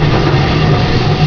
pizzawheel.wav